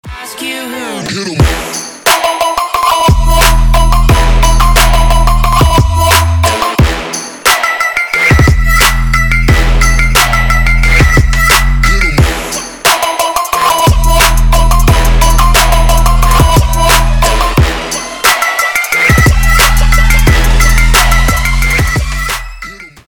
• Качество: 320, Stereo
Electronic
Trap
club